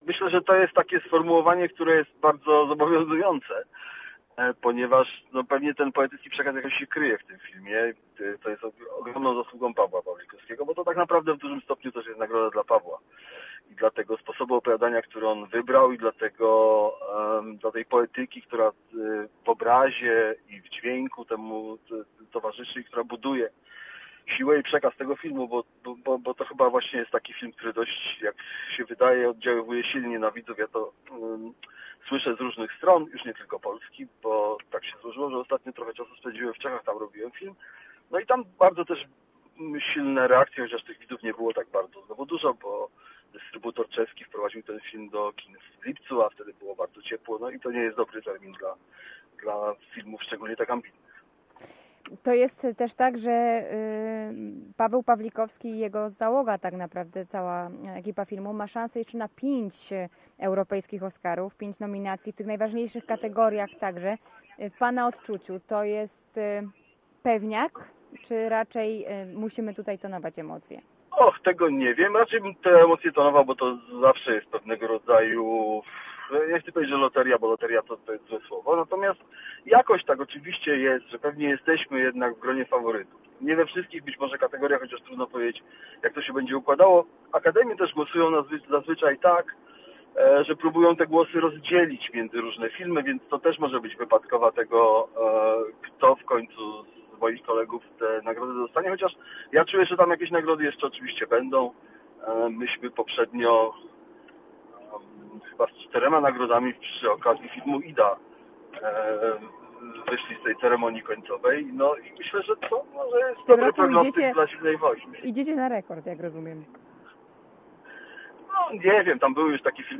[ROZMOWA]